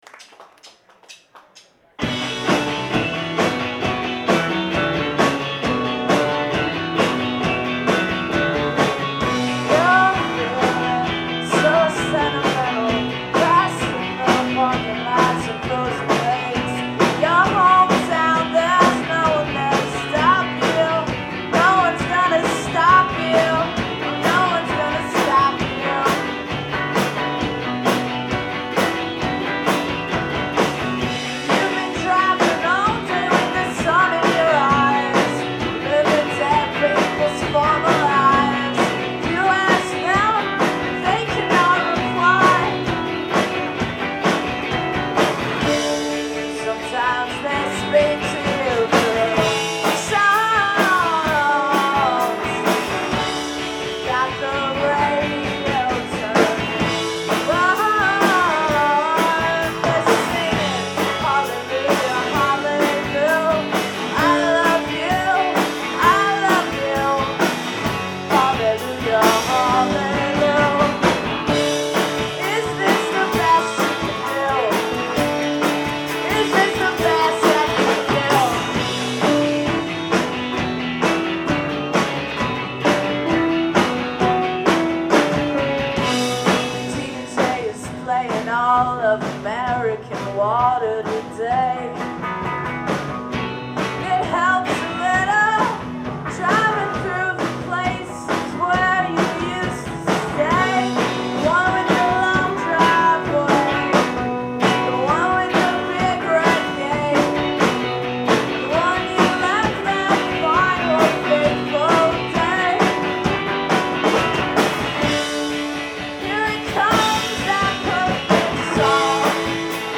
Live at Great Scott
in Allston, Mass.